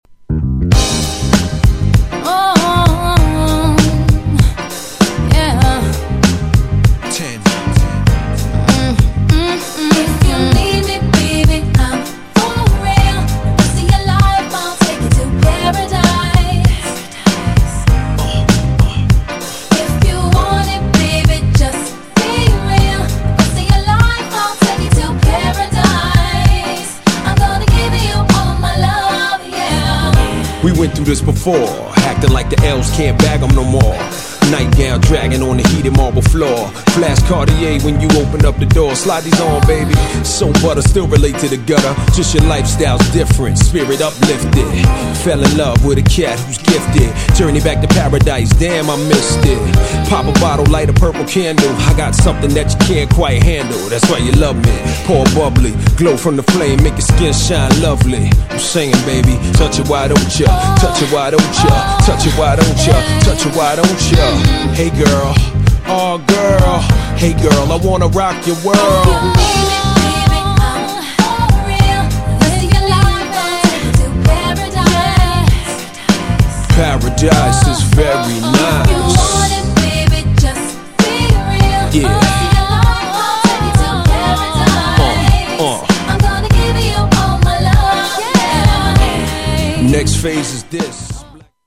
GENRE Hip Hop
BPM 96〜100BPM